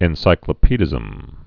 (ĕn-sīklə-pēdĭzəm)